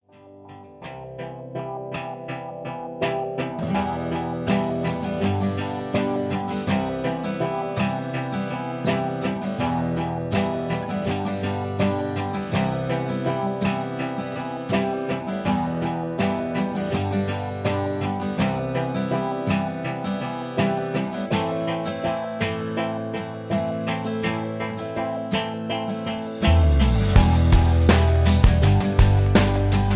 backing tracks